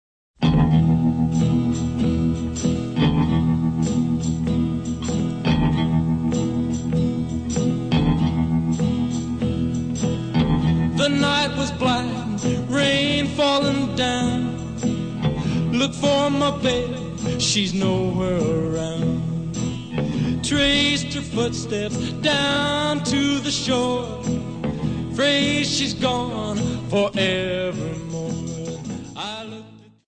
Oldies